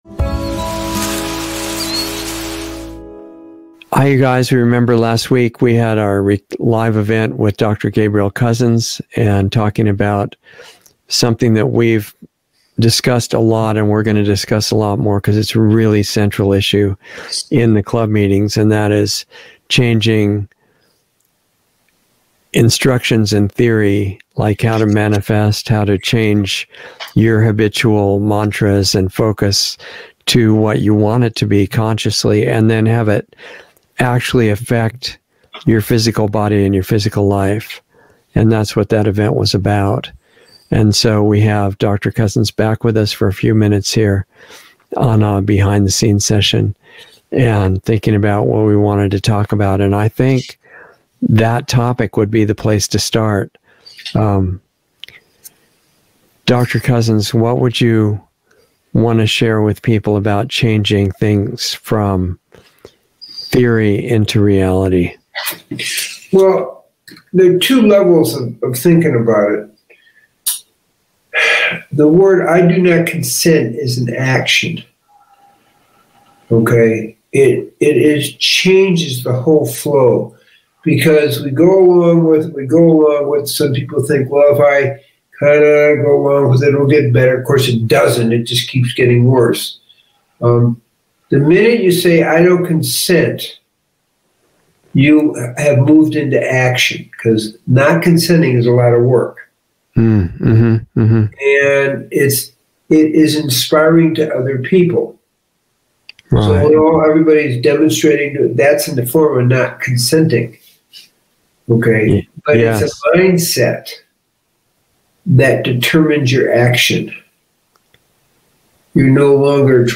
Insider Interview 12/6/21 0:12:48 הירשם 19 lostartsradio 3 שנים 13 צפיות תרומה אנא התחברו לתרומה MP3 להוסיף ל רוצה לראות את זה שוב מאוחר יותר?